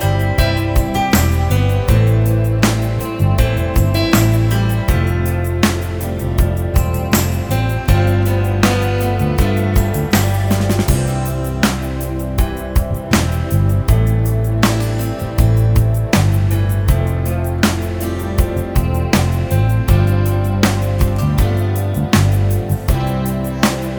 Reggae Version Easy Listening 3:46 Buy £1.50